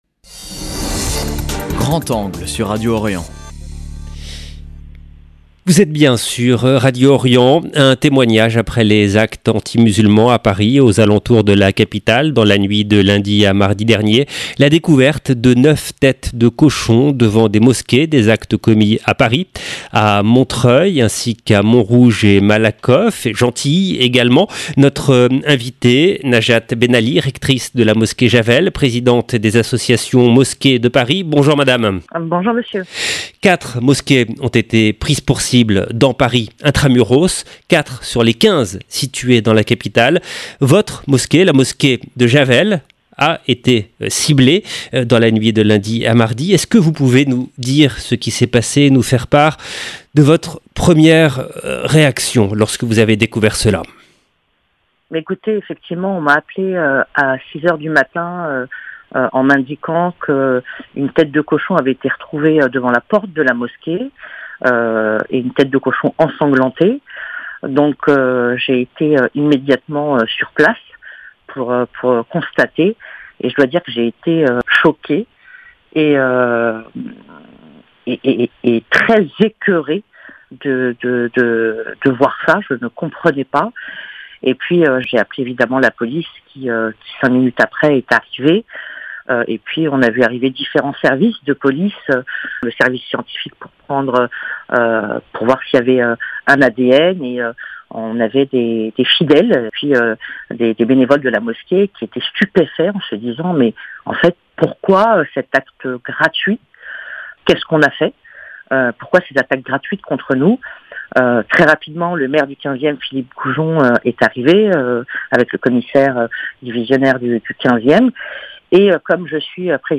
La découverte de neuf têtes de cochon devant des mosquées. Des actes commis simultanément à Paris, à Montreuil , ainsi qu'à Montrouge et Malakoff et Gentilly. Entretien